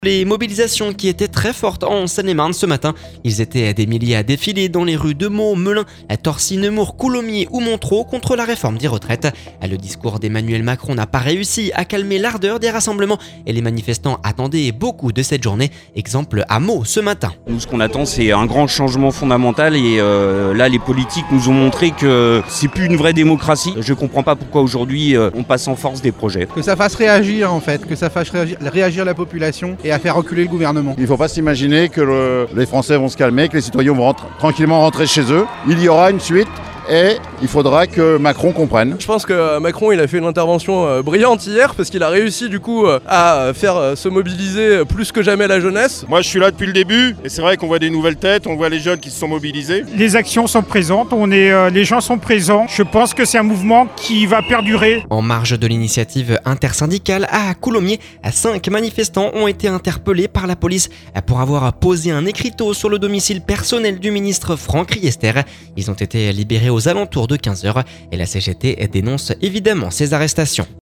Exemple à Meaux ce matin…